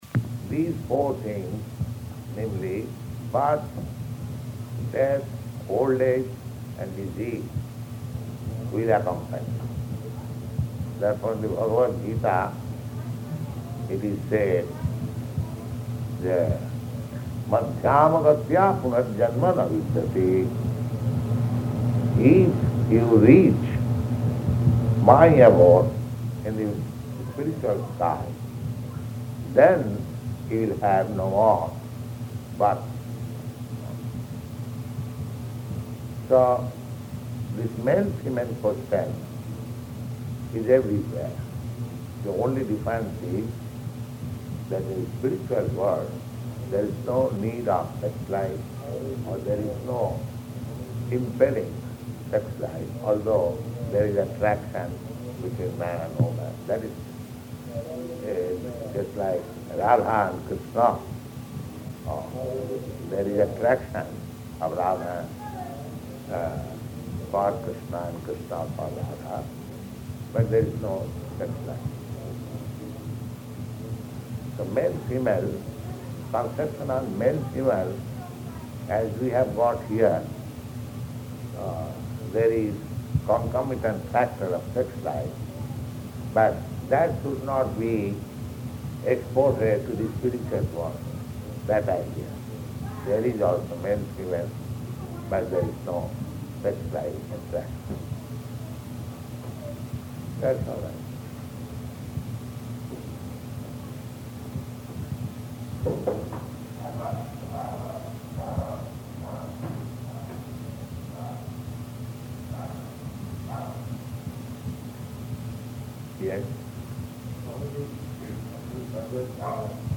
Room Conversation
Location: Boston